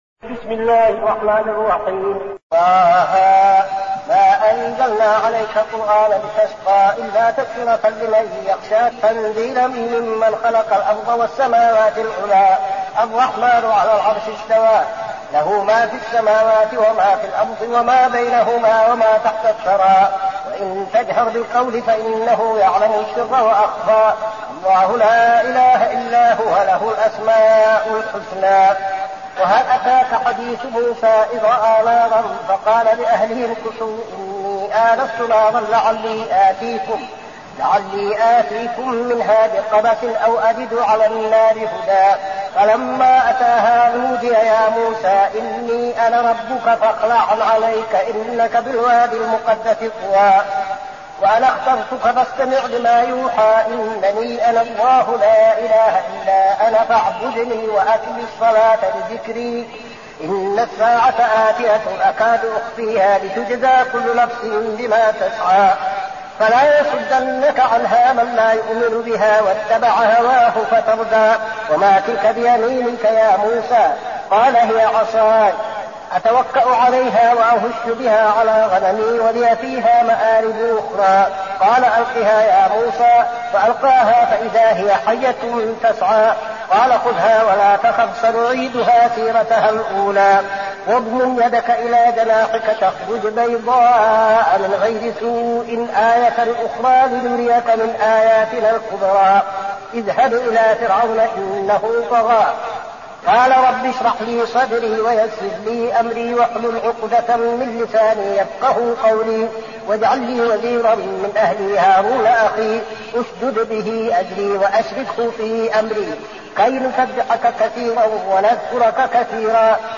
المكان: المسجد النبوي الشيخ: فضيلة الشيخ عبدالعزيز بن صالح فضيلة الشيخ عبدالعزيز بن صالح طه